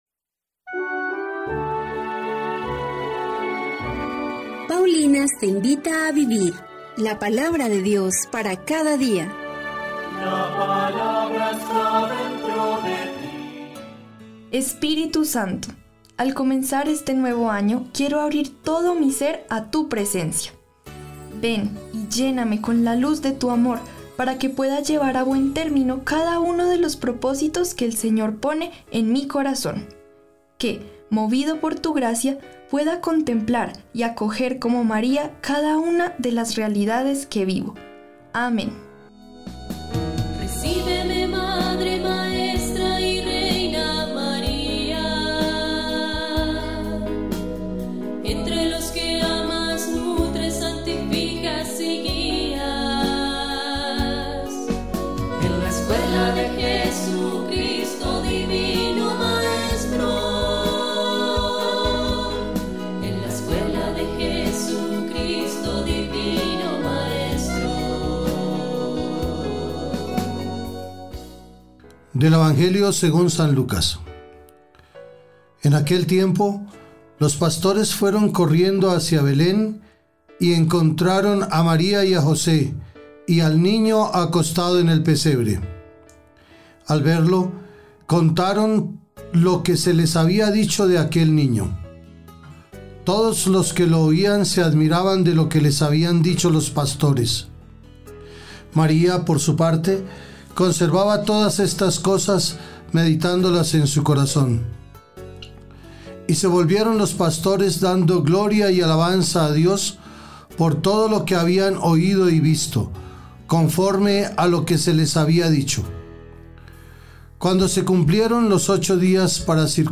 Lectura de la Carta a los Hebreos 5, 1-10